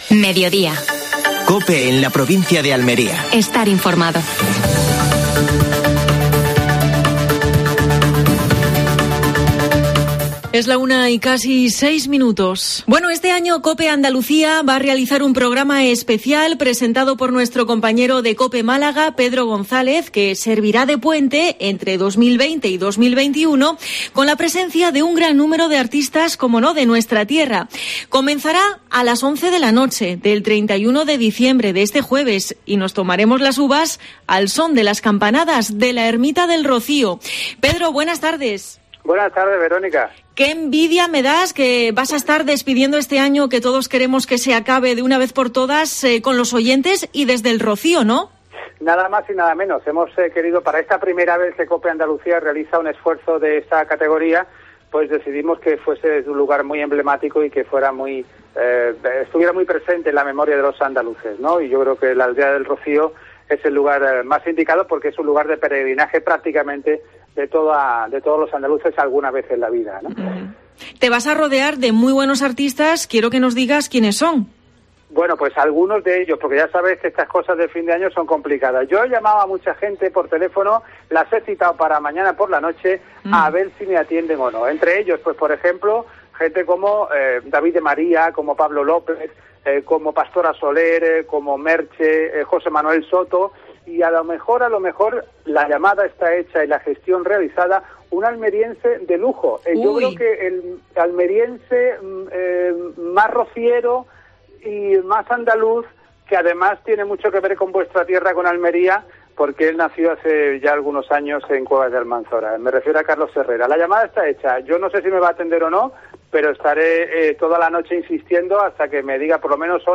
AUDIO: Actualidad en Almería. Entrevista